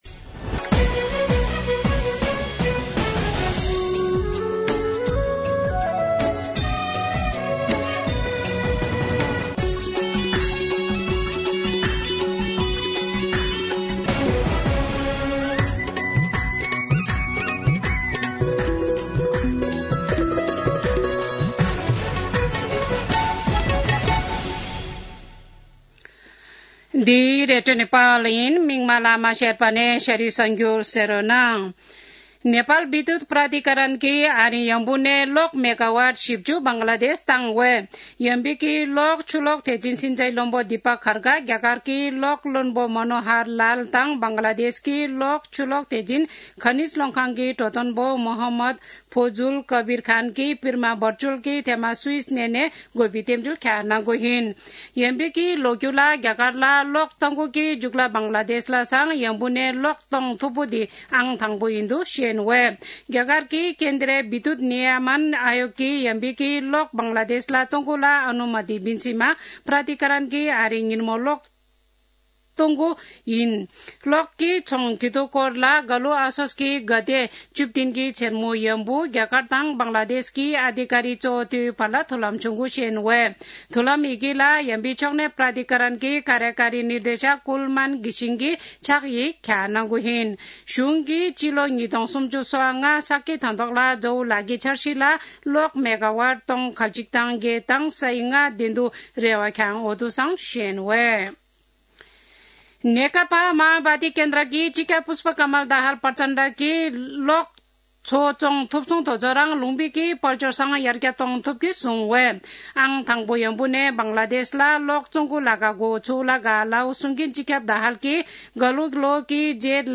An online outlet of Nepal's national radio broadcaster
शेर्पा भाषाको समाचार : १ मंसिर , २०८१
Sherpa-News.mp3